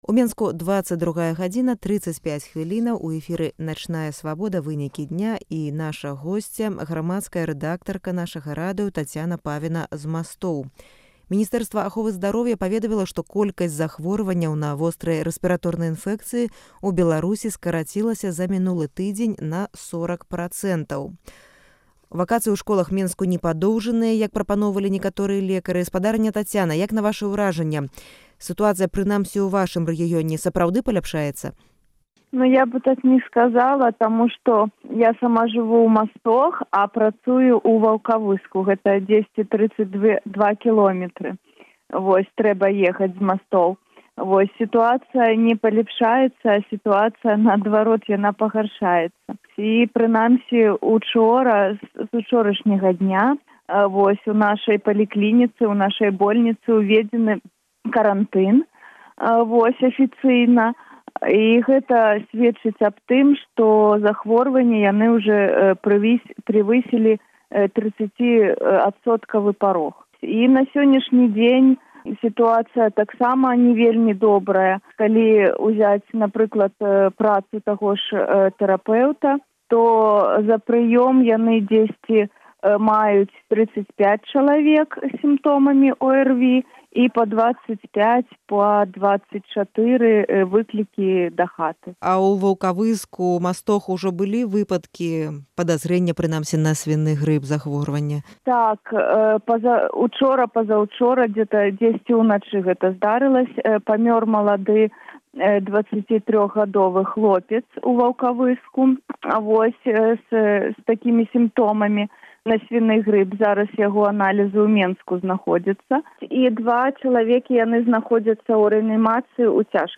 Гутарка